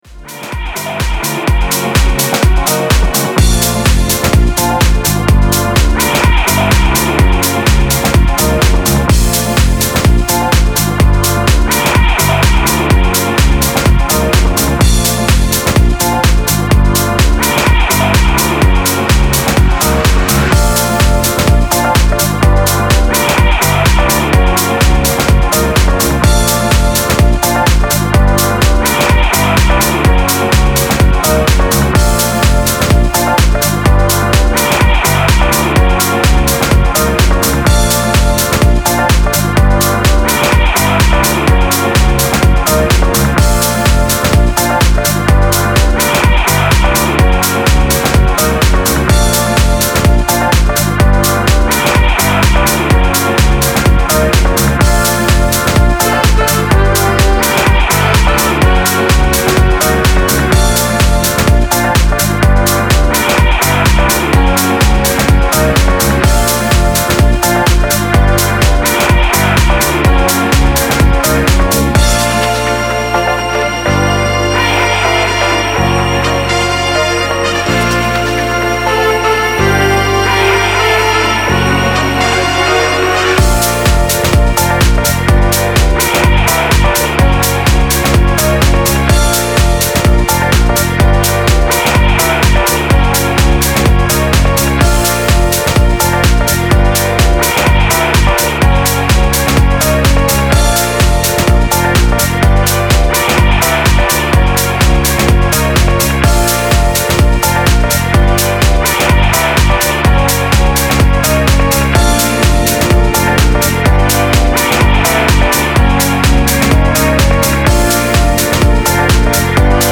This track will definitely get the dance floor moving!